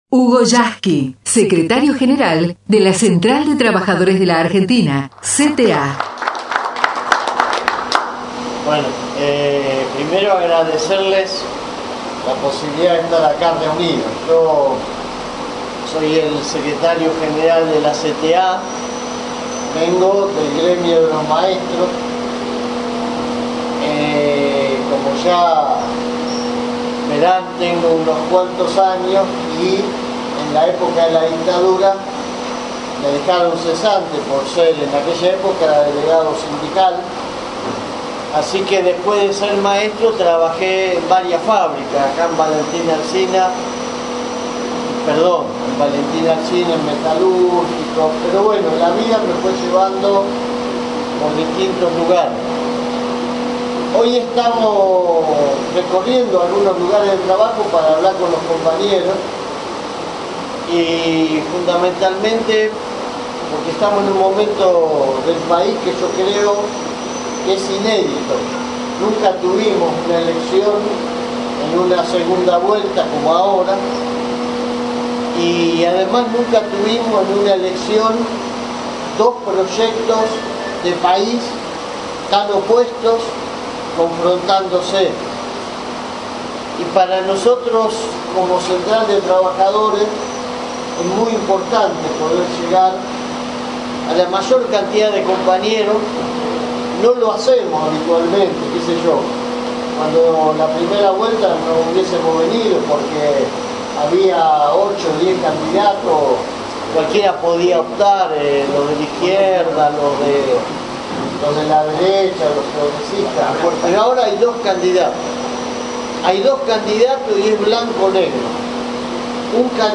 VOTO A VOTO POR SCIOLI – HUGO YASKY en fábrica manufacturas de cuero en Pompeya